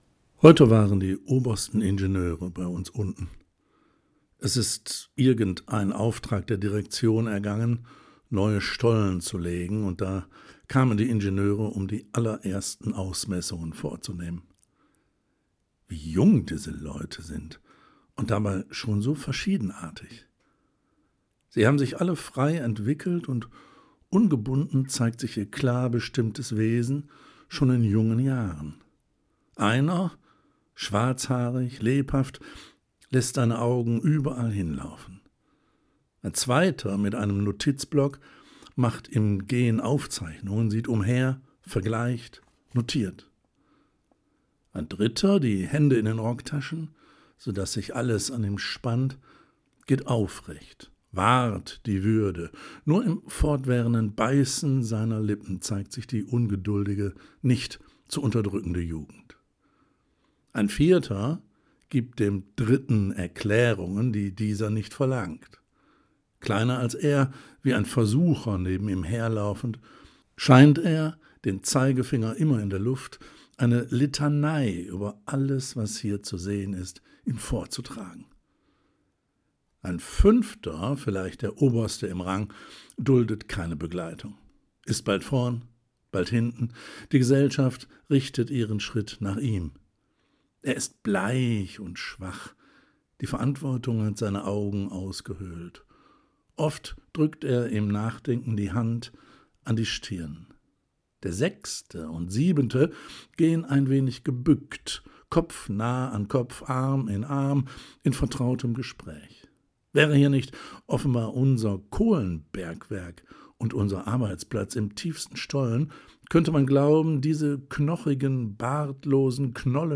Hochwertige Literatur, vorgelesen von professionellen Sprecherinnen und Sprechern